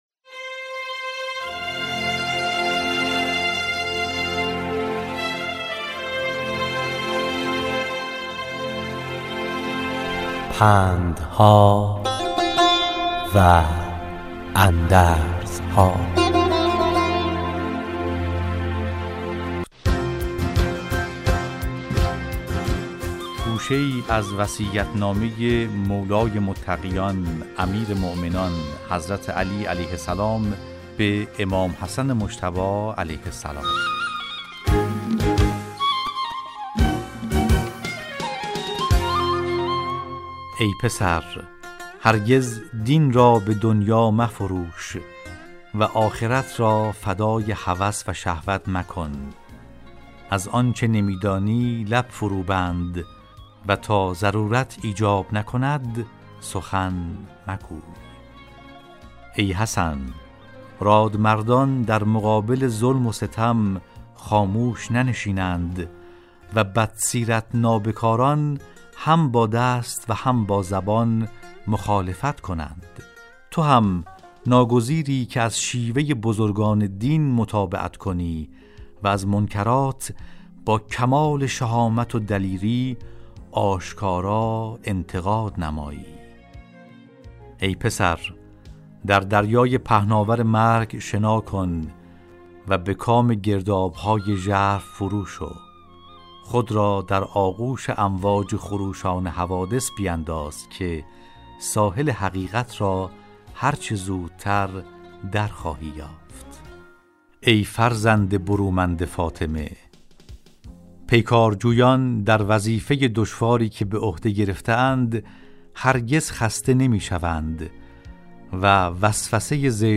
راوی برای شنوندگان عزیز صدای خراسان، حکایت های پندآموزی را روایت می کند